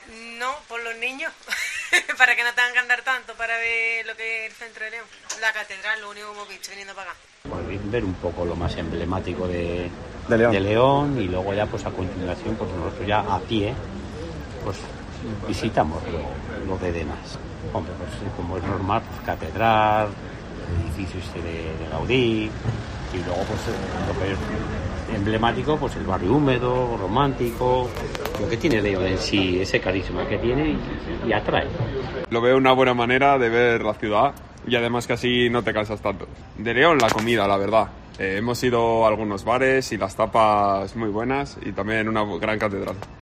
Usuarios del Tren turístico opinan sobre León